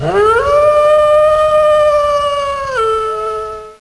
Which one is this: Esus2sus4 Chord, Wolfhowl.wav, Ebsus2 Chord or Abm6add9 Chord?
Wolfhowl.wav